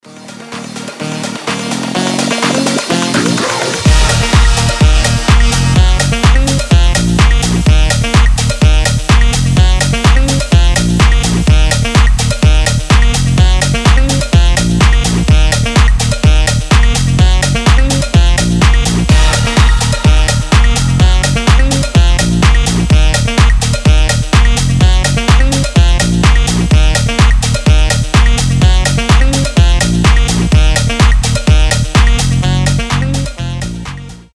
громкие
зажигательные
retromix
Саксофон
house
ремиксы